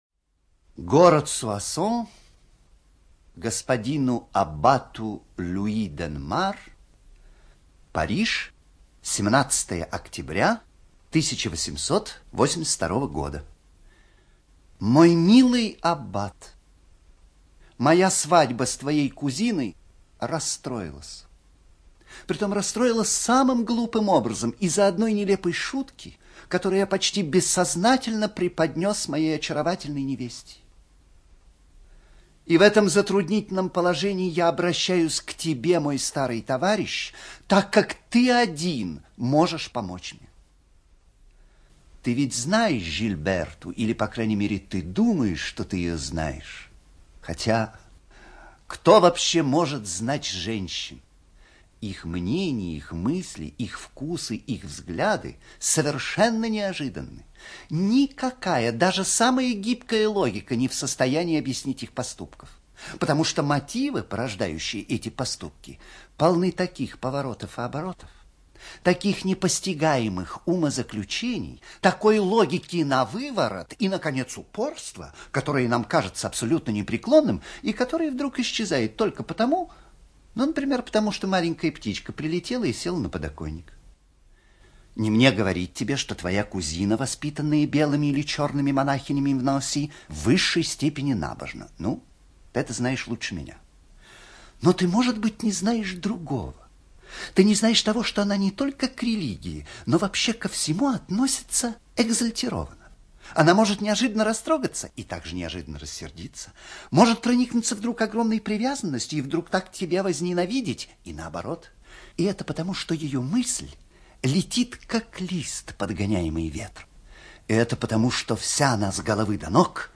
ЧитаетКторов А.